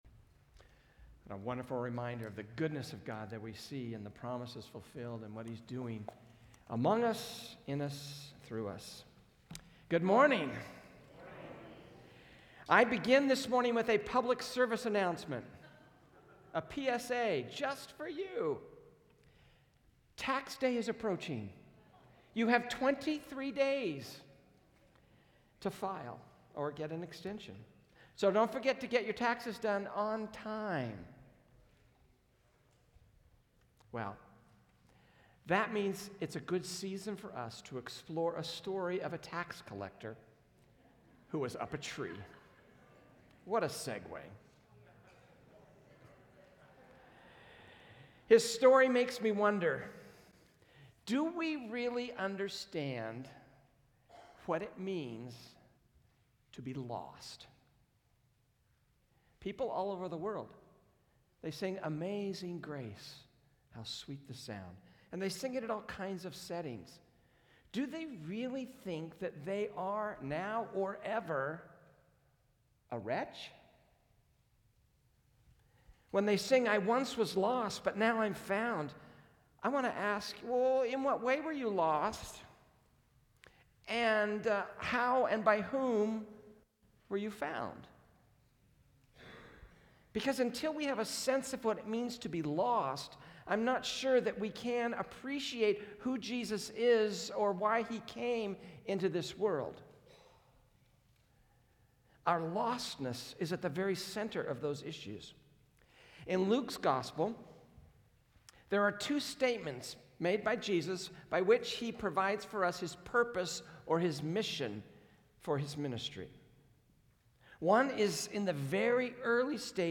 PCC Sermons